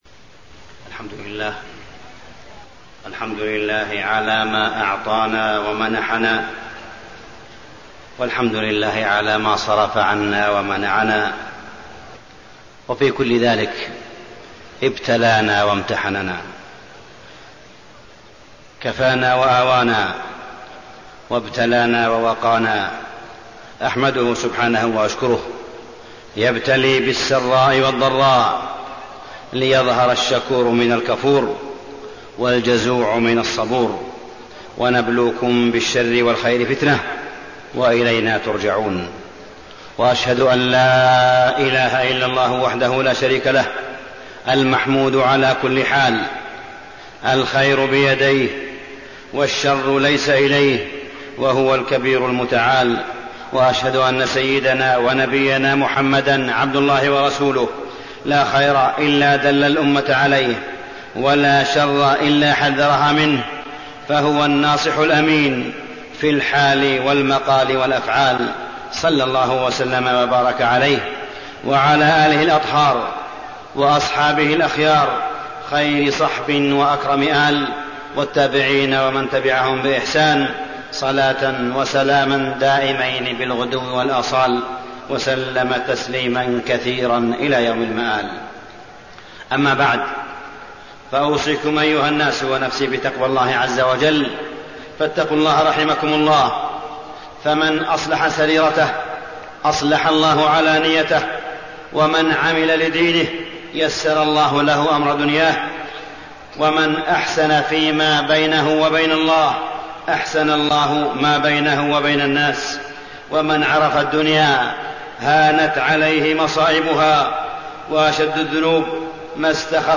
تاريخ النشر ٧ صفر ١٤٣١ هـ المكان: المسجد الحرام الشيخ: معالي الشيخ أ.د. صالح بن عبدالله بن حميد معالي الشيخ أ.د. صالح بن عبدالله بن حميد الكوارث والنكبات عقوبات وتأملات